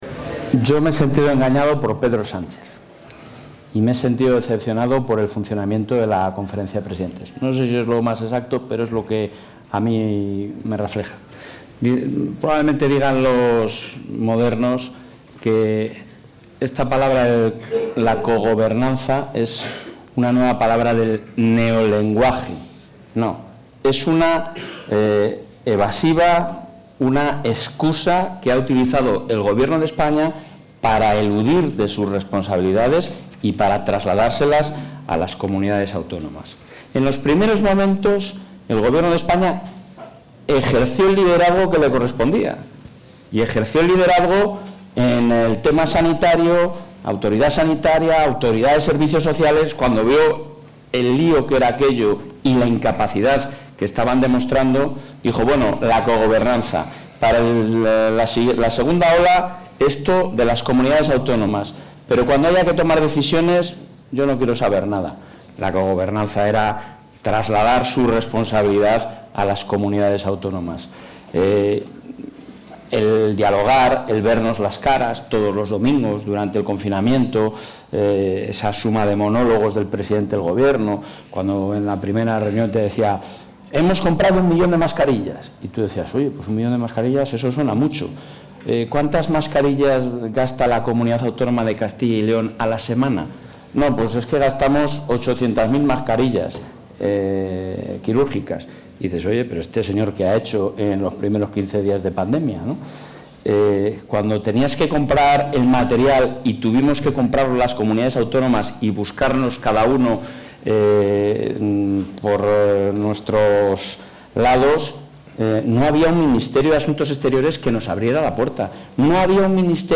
Intervención del presidente de la Junta.
El presidente de la Junta de Castilla y León ha participado, junto a José Maria Aznar, en una sesión de liderazgo organizada por el Instituto Atlántico de Gobierno y la Universidad Francisco de Vitoria